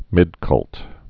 (mĭdkŭlt)